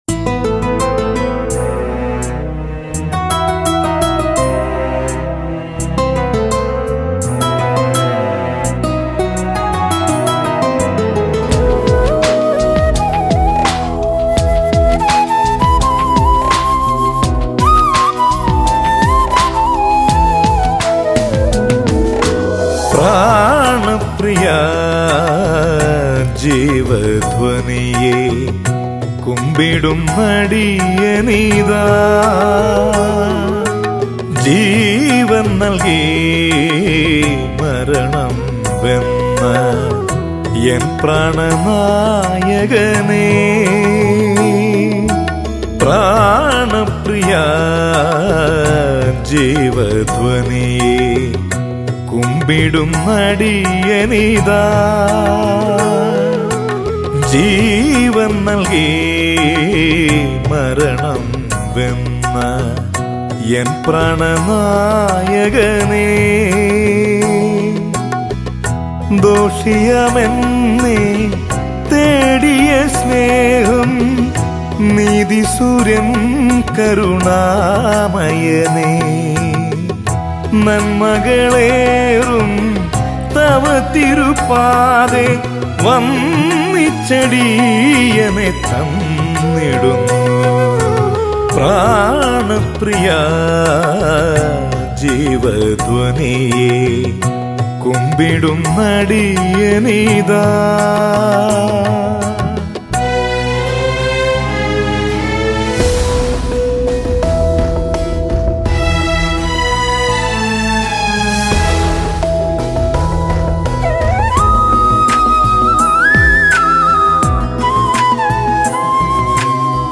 Christian Devotional Songs & Video Albums